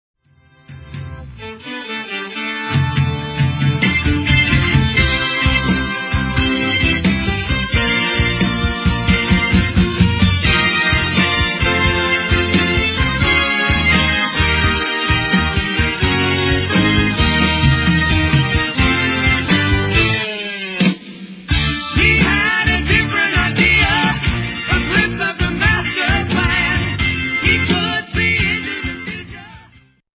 DRUMS
BASS/VOCALS
VIOLIN/VOCALS
LEAD VOCALS/KEYBOARD
LEAD & ACOUSTIC GUITAR
melodic yet progressive sound